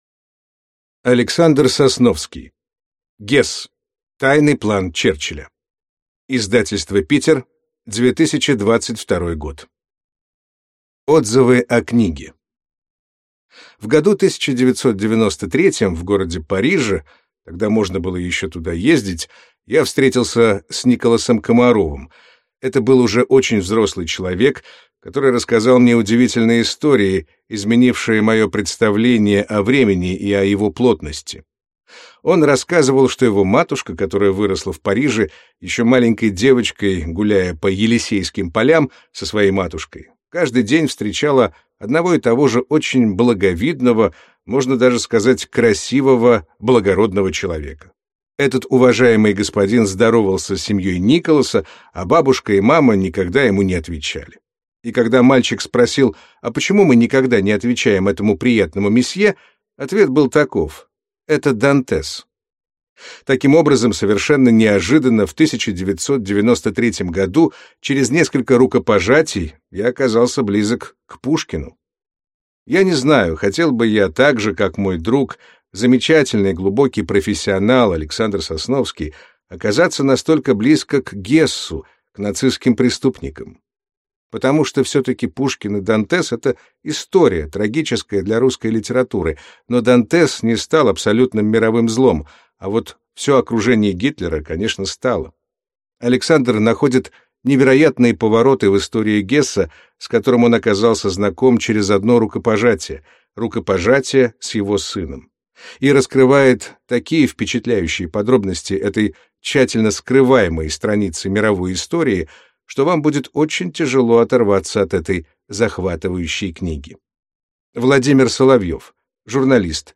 Аудиокнига «ГESS». Тайный план Черчилля | Библиотека аудиокниг